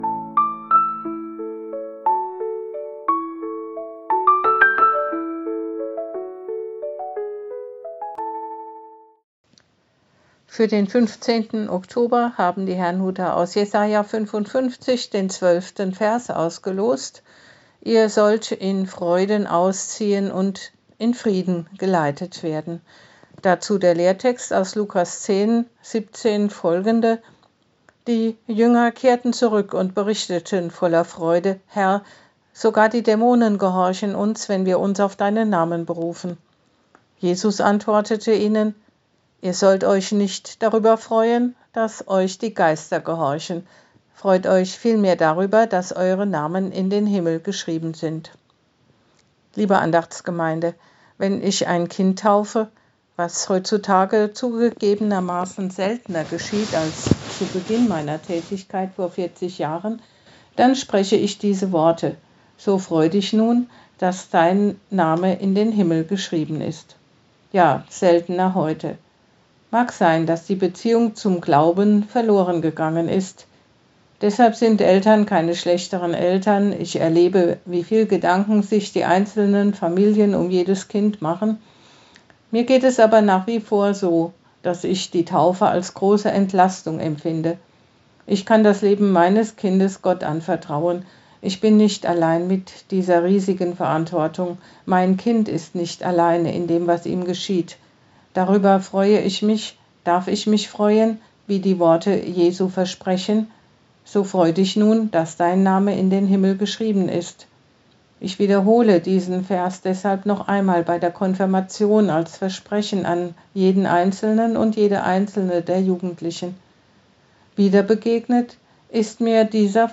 Losungsandacht für Mittwoch, 15.10.2025
Text und Sprecherin